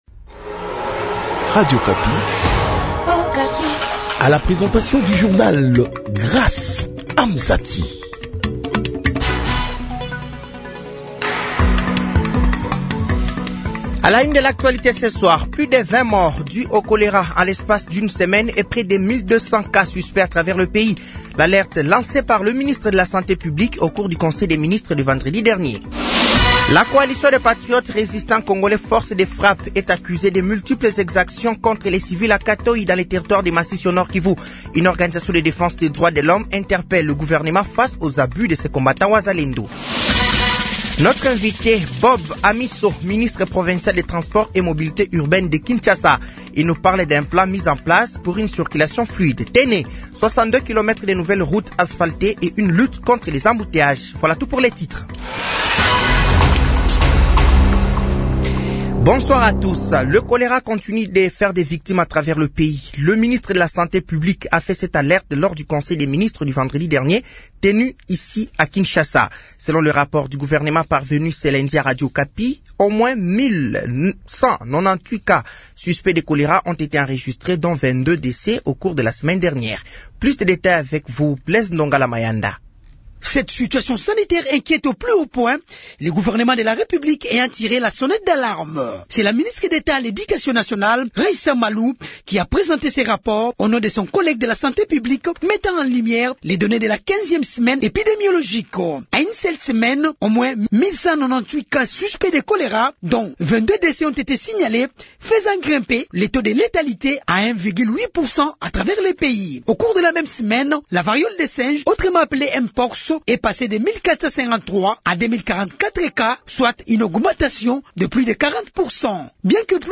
Journal français de 18h de ce lundi 28 avril 2025